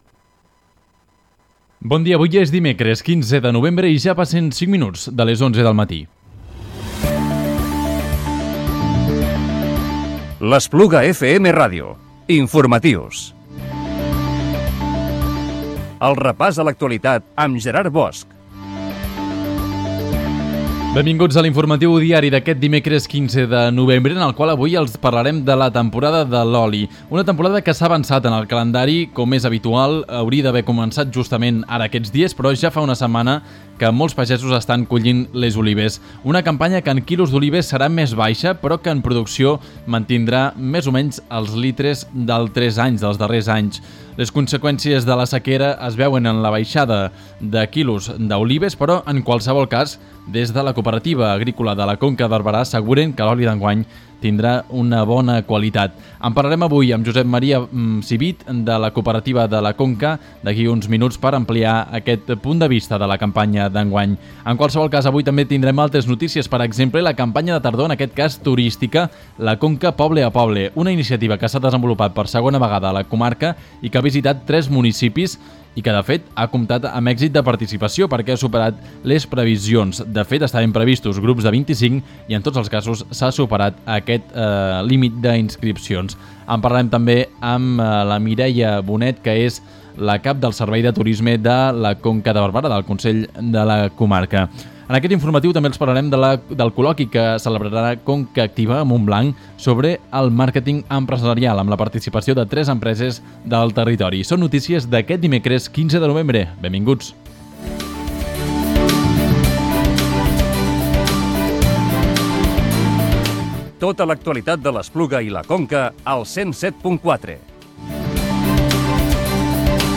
Informatiu Diari del dimecres 15 de novembre del 2017